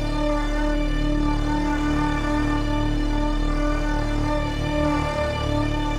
Index of /musicradar/dystopian-drone-samples/Non Tempo Loops
DD_LoopDrone2-C.wav